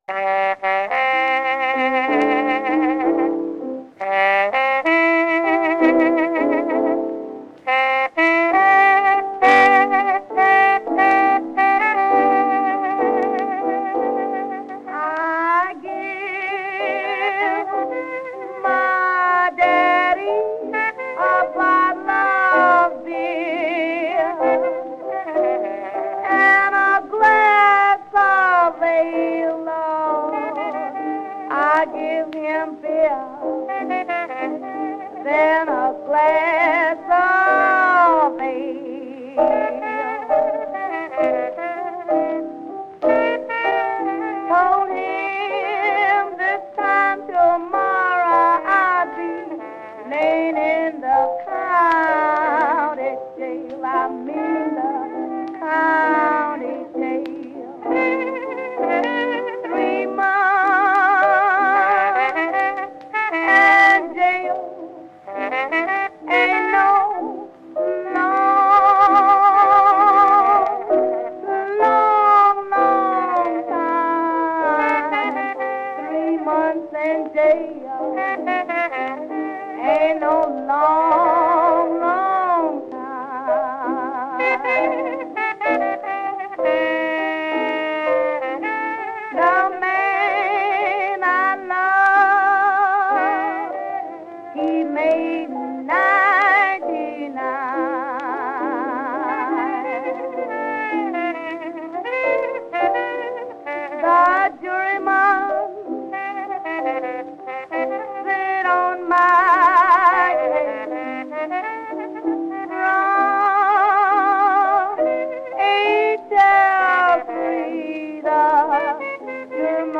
out-of-copyright early blues, jazz and folk recordings